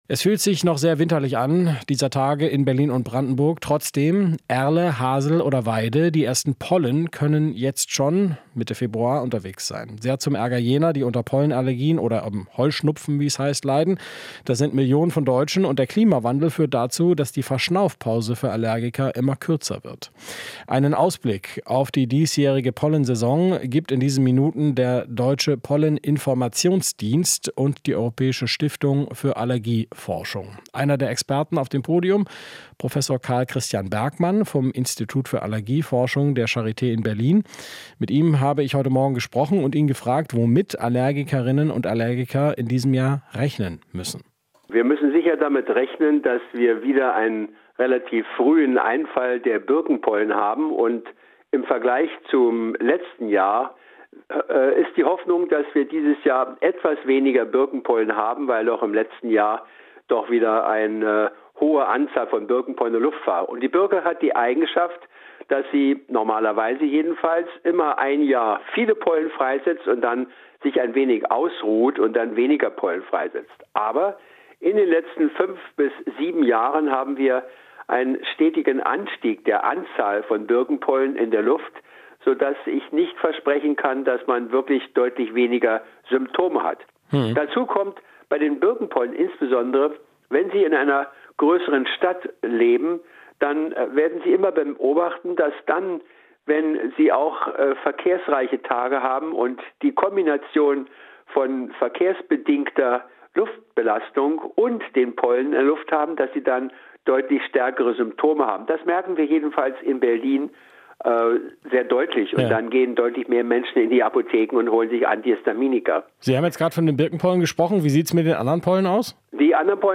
Interview - Start der Pollensaison: Früher dank Klimawandel | rbb24 Inforadio